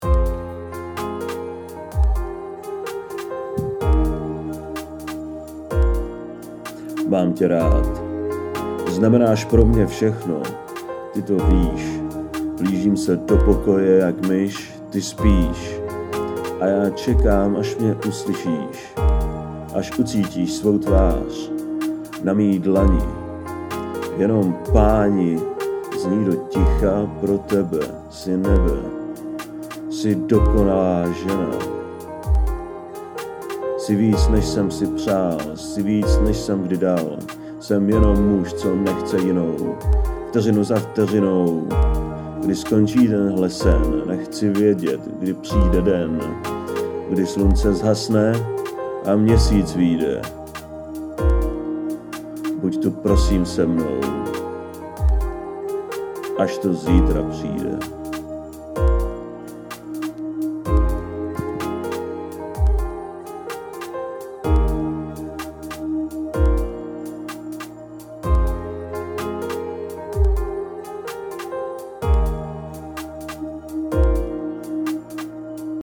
Anotace: Krátký rap muze oslavujici zenu